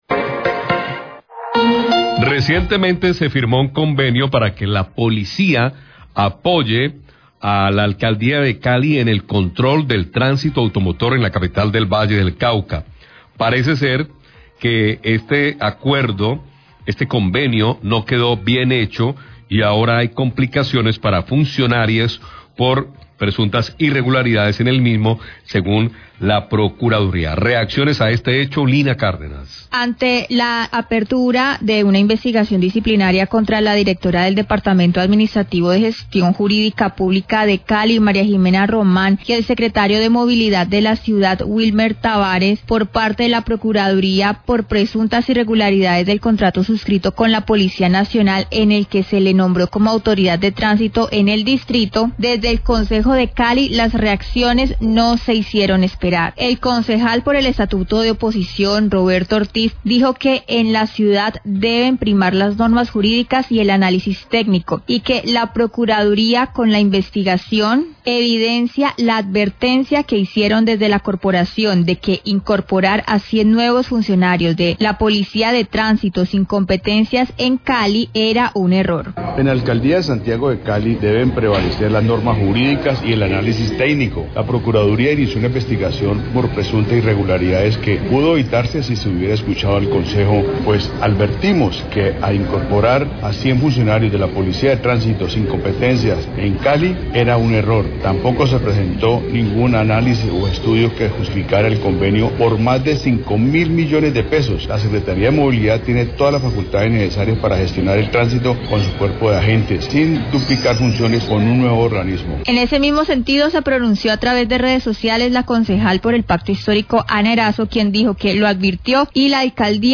Concejal Roberto Ortiz se pronunció sobre investigación de Procuraduría al convenio con Policía, RCN Radio 1235pm
Radio